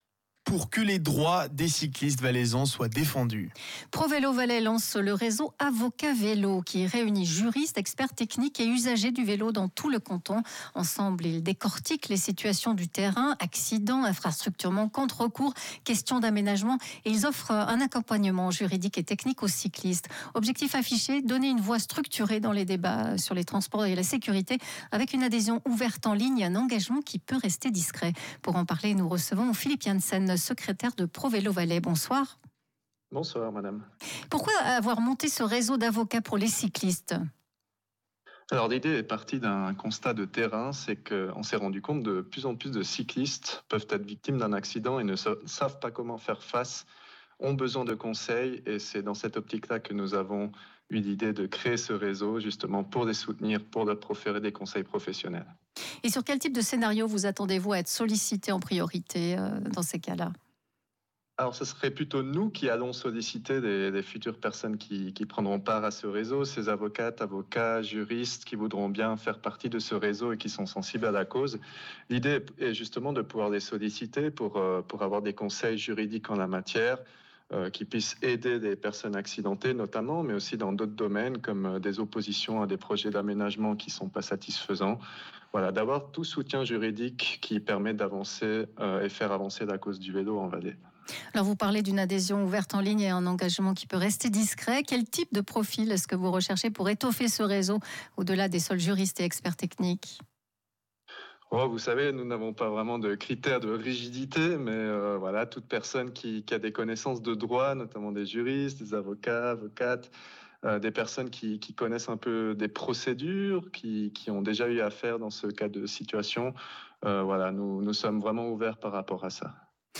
Rhône FM, 09.12.2025, journal de 18:00